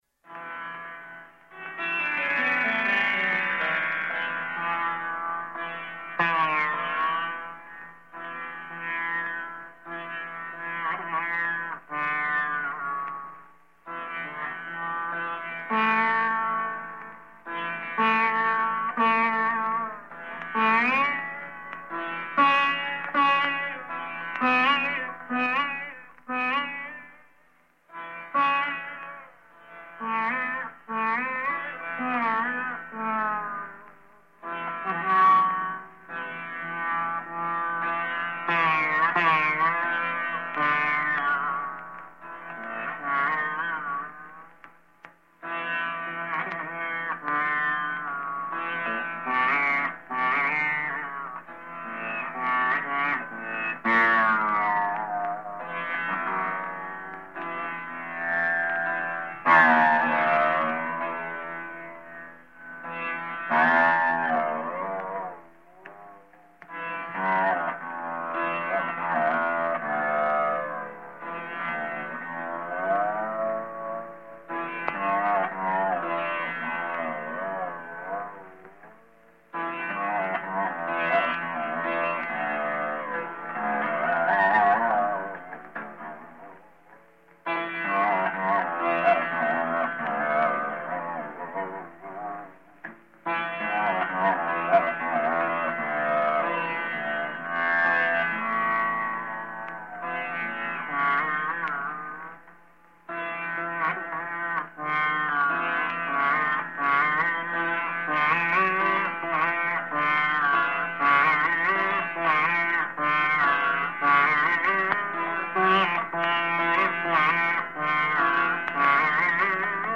Alap
SURBAHAR